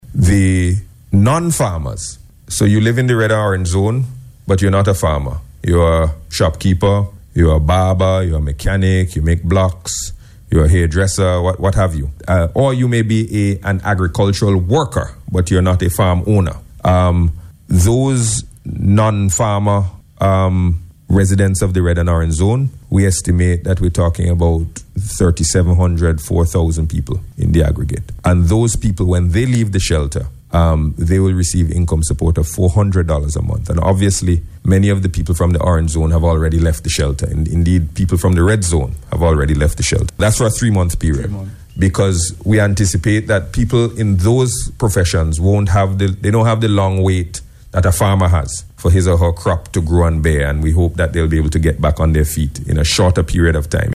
Speaking on radio yesterday, Minister Gonsalves said so far significant funds have been spent on clean-up activities, and this week will see additional funds being expended on income support.